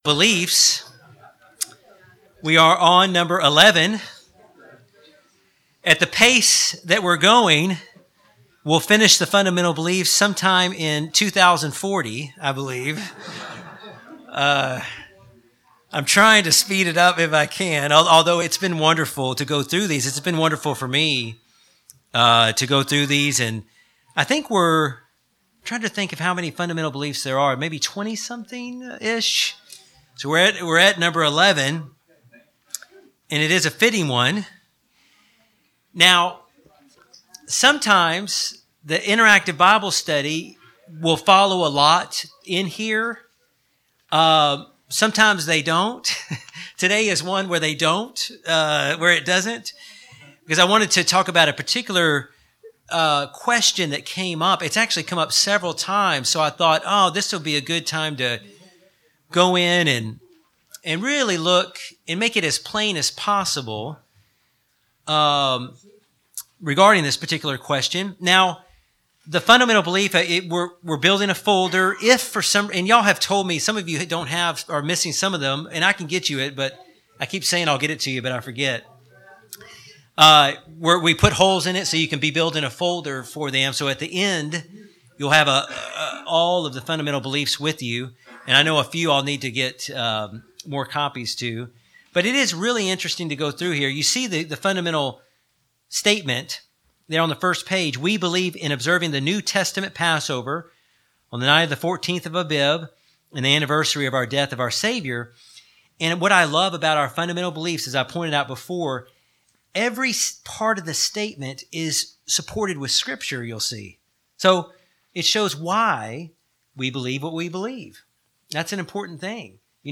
Bible Study about what the important symbols of Passover point to.